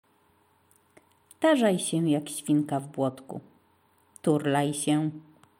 świnka
swinka.mp3